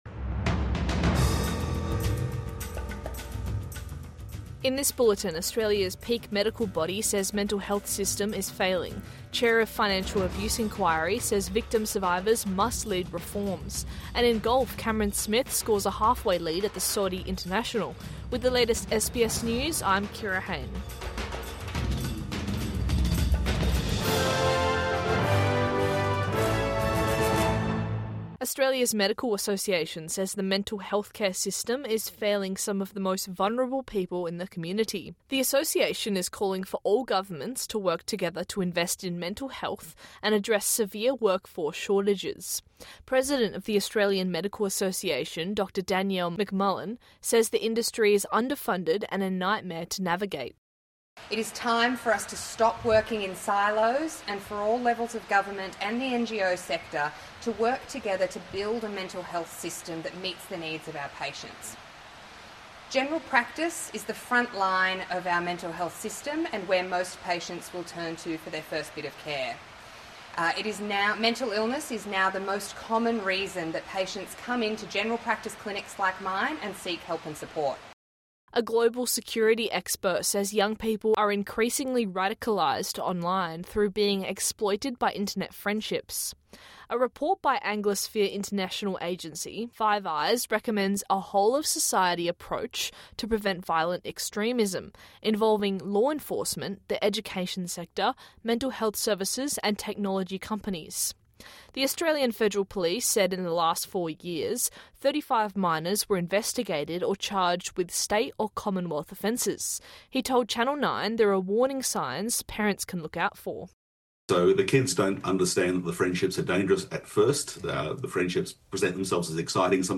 Midday News Bulletin 6 December 2024